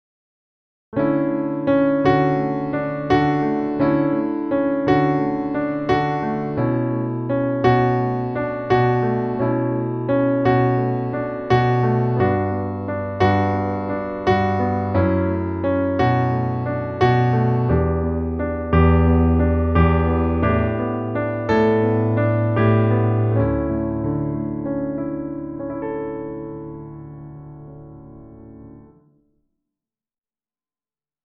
アルペジオ演奏2
アルペジオ演奏2.mp3